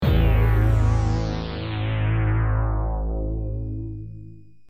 Sequencial Circuits - Prophet 600 52